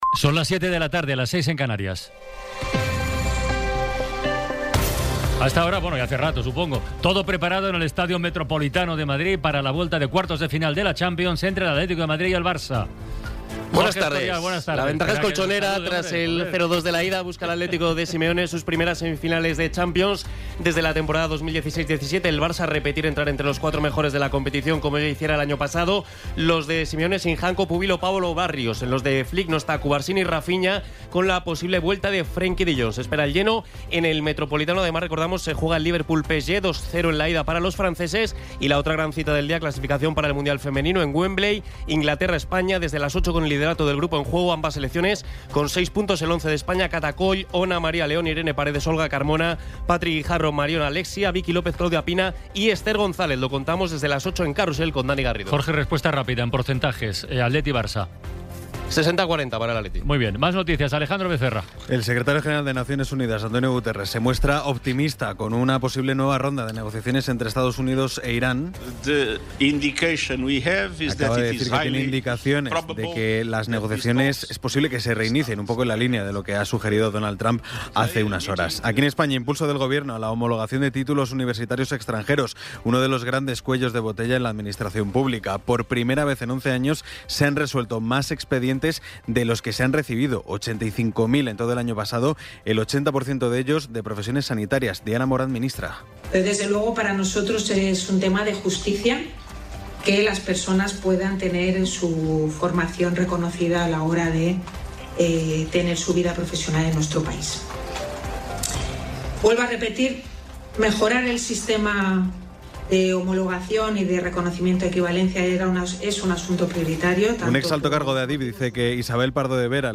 Resumen informativo con las noticias más destacadas del 14 de abril de 2026 a las siete de la tarde.